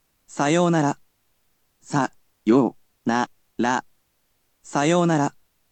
We’ll need to enlist the help of our lovely computer friend, QUIZBO™先生せんせい！
Click on the sound players to ask him to read something for you.